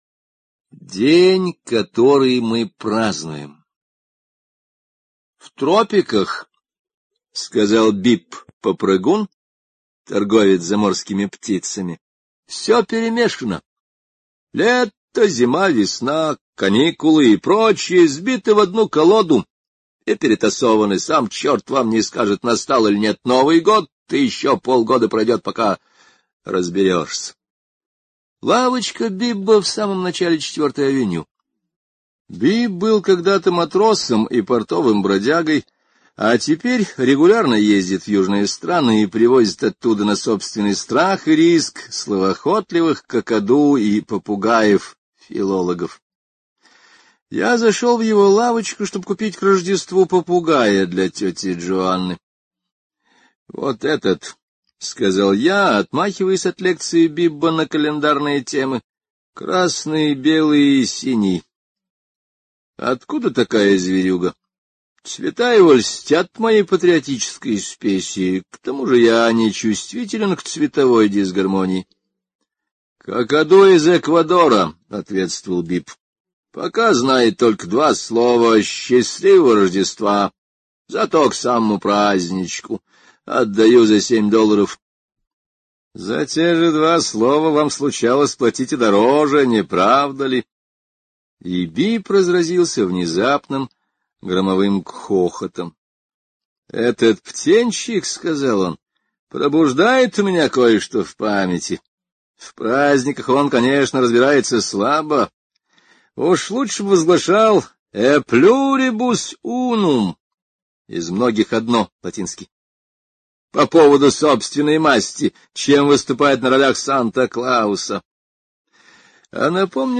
День, который мы празднуем — слушать аудиосказку Генри О бесплатно онлайн